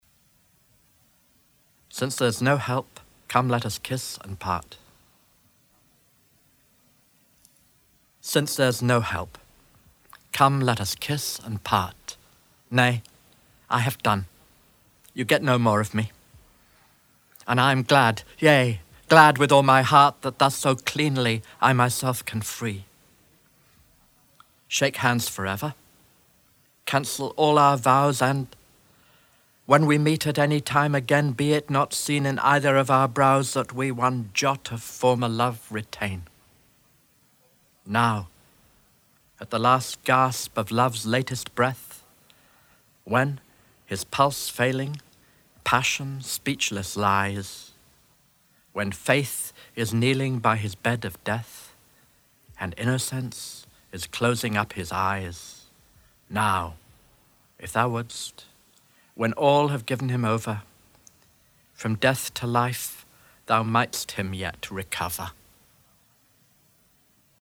Since there’s no help, come let us kiss and part by Michael Drayton read by Adrian Mitchell